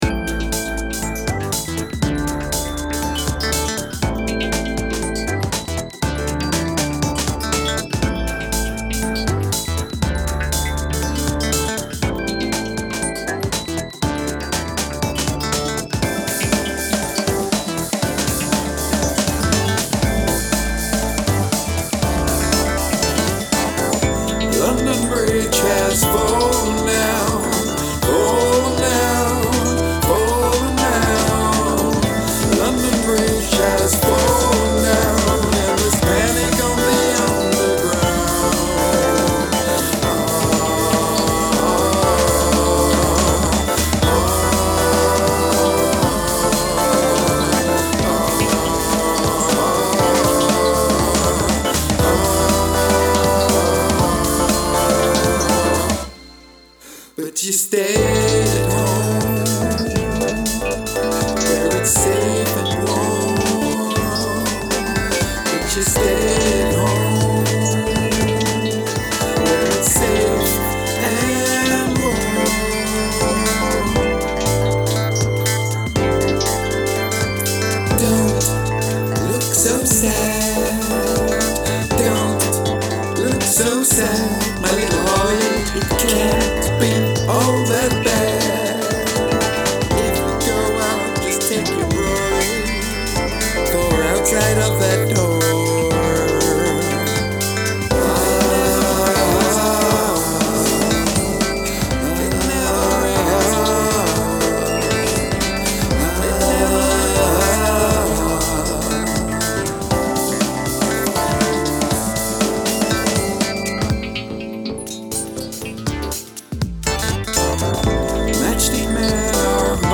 experimental/free Jazz sounding song
in nursery rhyme form